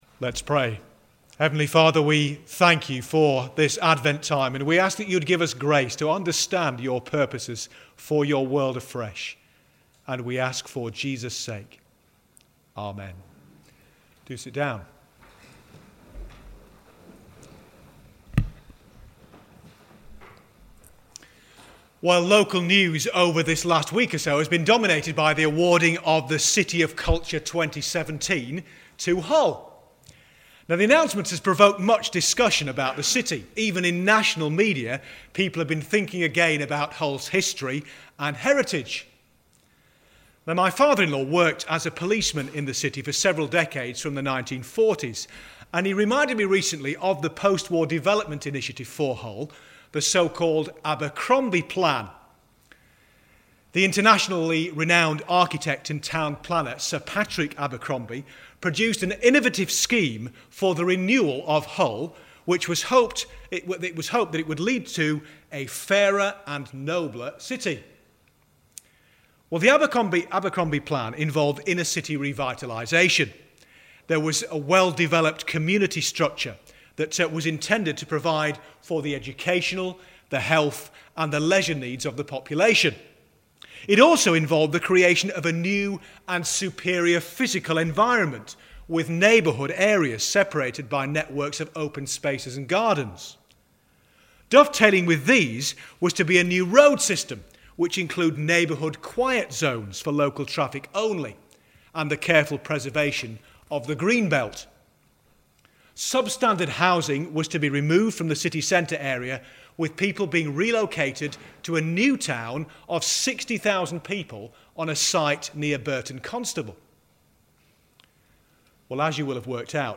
1 December Advent Music Service sermon